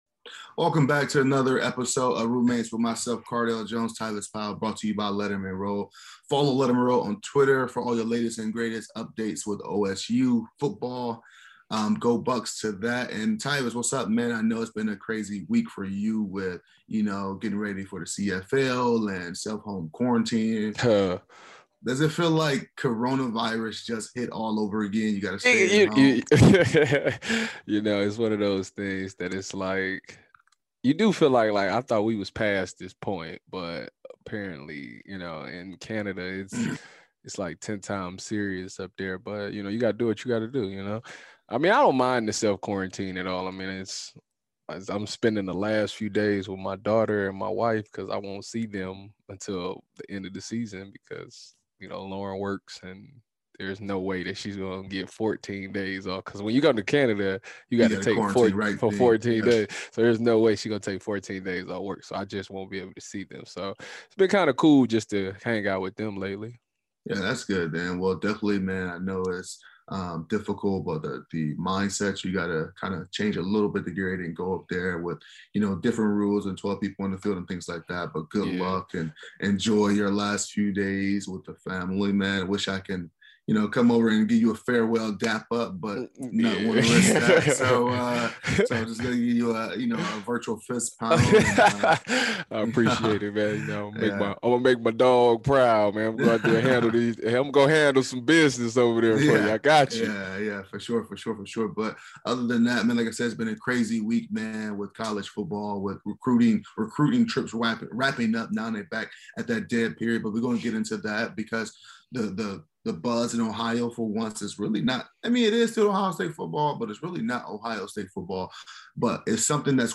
Cardale and Tyvis is hosted by Ohio State football legends, Cardale Jones and Tyvis Powell. Each week the guys interview interesting guests and give listeners an inside look behind the scenes of college football and the NFL.